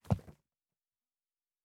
pgs/Assets/Audio/Fantasy Interface Sounds/Book 07.wav at master